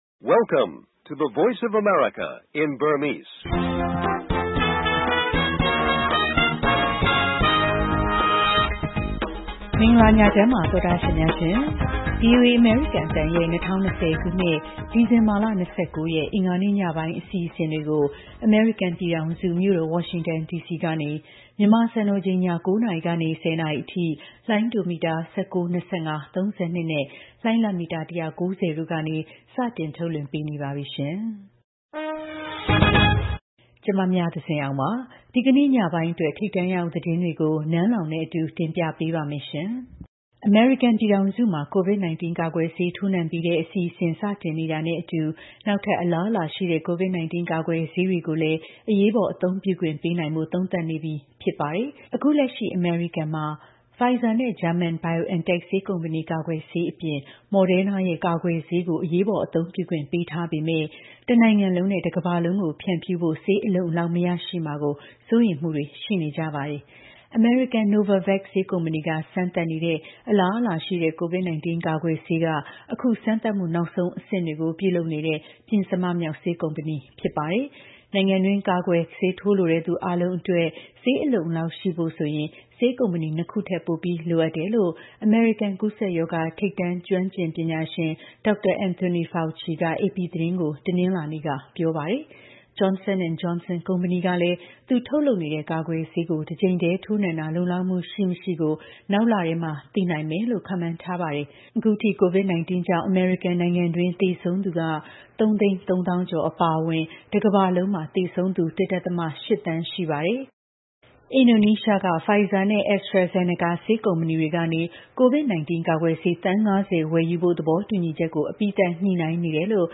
ထိပ်တန်းသတင်းများ
ဗွီအိုအေ ရေဒီယိုအစီအစဉ် တိုက်ရိုက်ထုတ်လွှင့်မှု